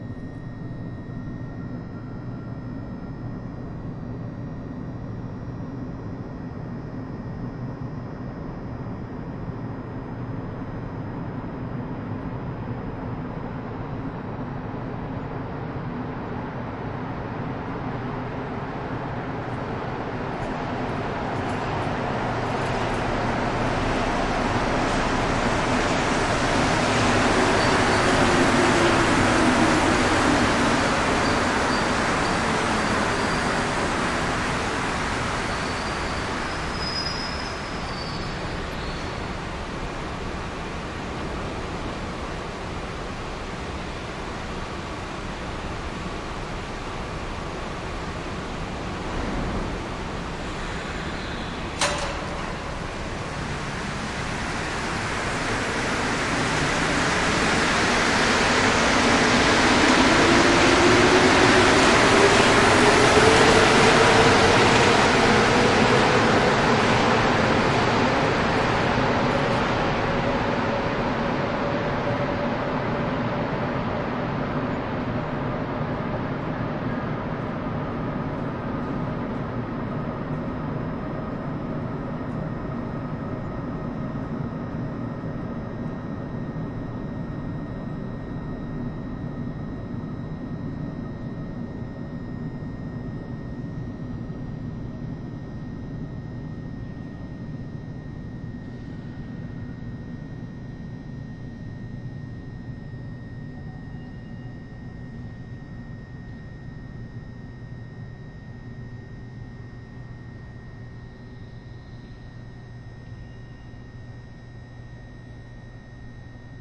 地铁站台列车出发到达
描述：记录在纽约市的地铁站。火车全程出发。到达记录在同一个文件中，但不完整。记录在奥林巴斯LS10上
标签： 到达 车站 地铁 火车 平台 地铁 前往
声道立体声